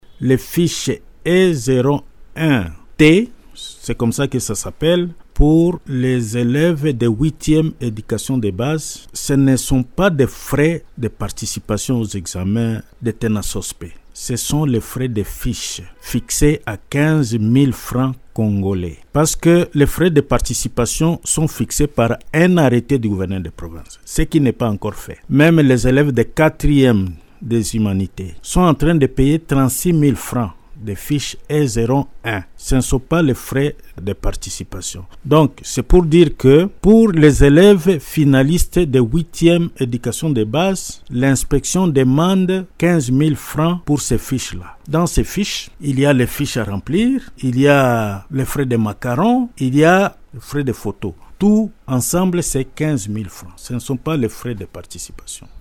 Cette précision est du Directeur provincial de l’Éducation Sud-Kivu 1, Léon Musagi, au cours d’une interview accordée à Radio Maendeleo, mardi 24 février 2026.